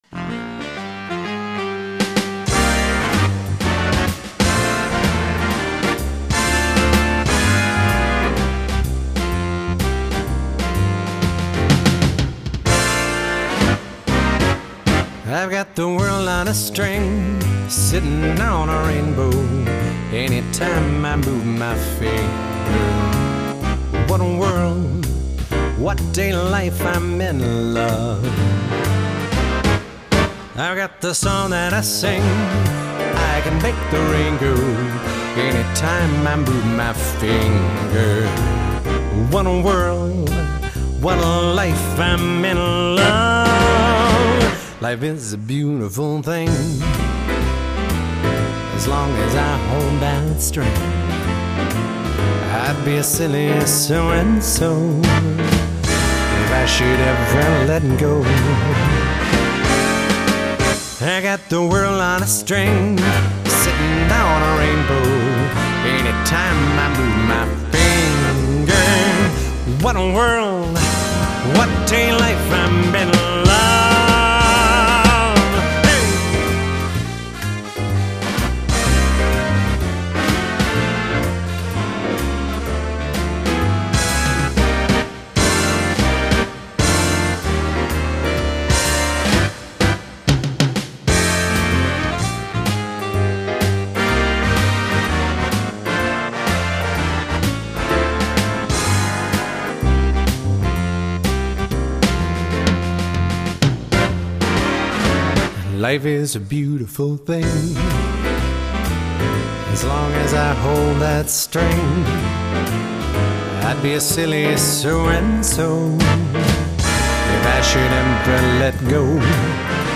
(Big Band Music)